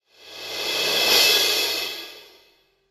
crash.ogg